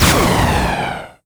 powerMissile.wav